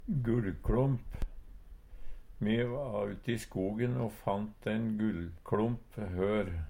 gullkLomp - Numedalsmål (en-US)
Hør på dette ordet Ordklasse: Substantiv hankjønn Attende til søk